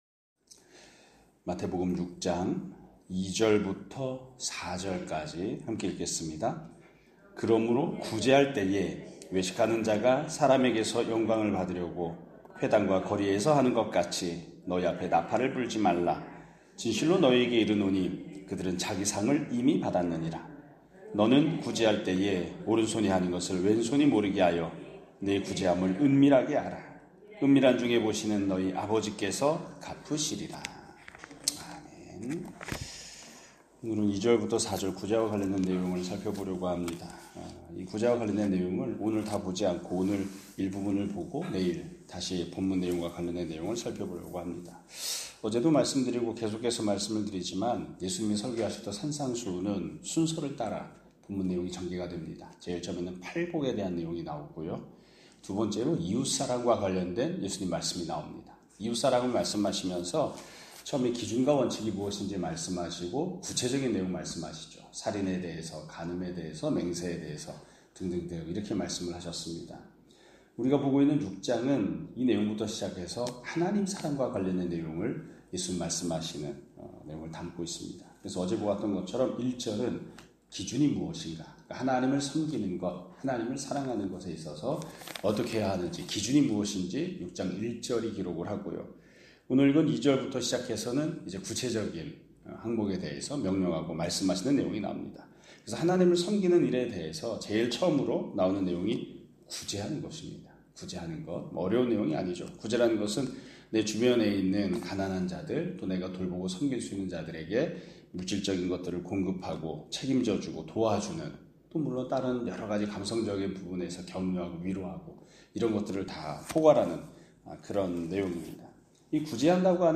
2025년 6월 10일(화요일) <아침예배> 설교입니다.